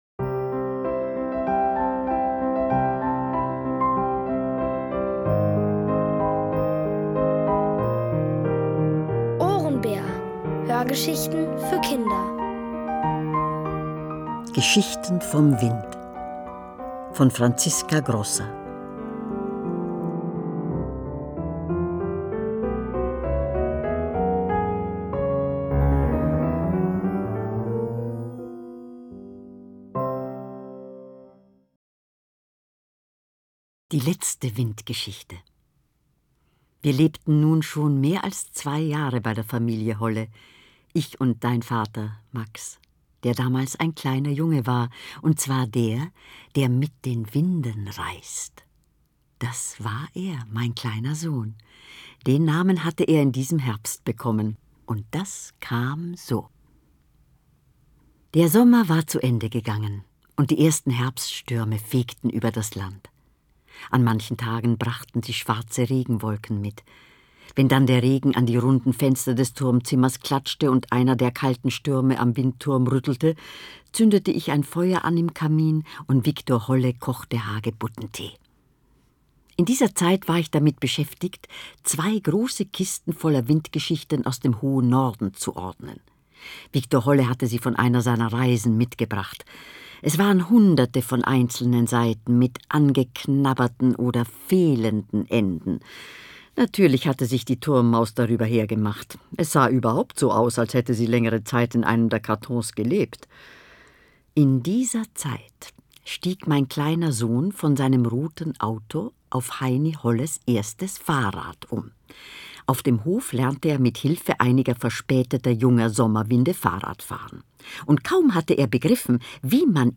Von Autoren extra für die Reihe geschrieben und von bekannten Schauspielern gelesen.
Es liest: Elfriede Irrall.